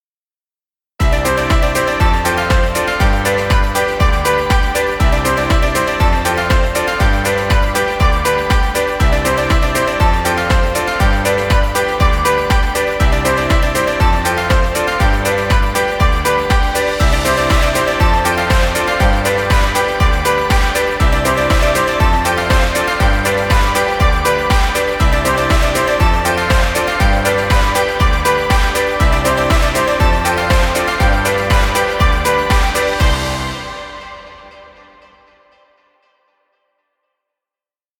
Background music Royalty Free.
Stock Music.